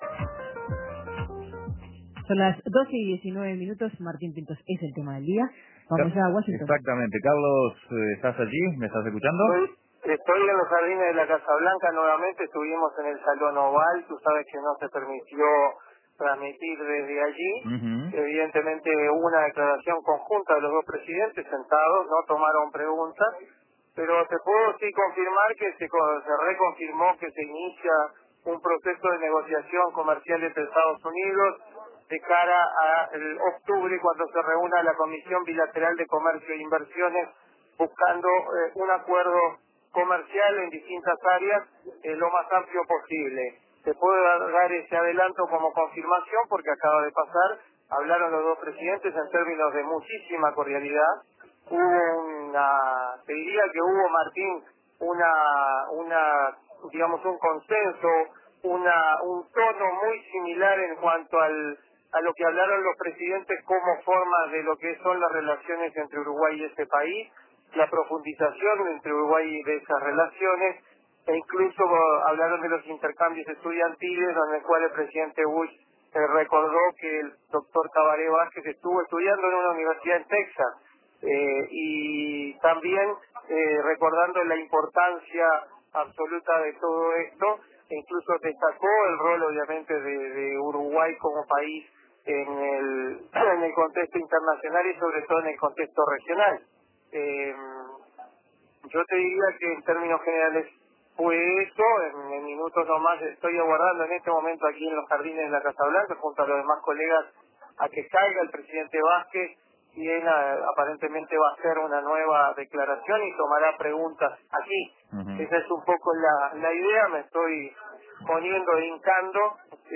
antes, durante y después de la conferencia de prensa conjunta que dieron Vázquez y Bush en la Casa Blanca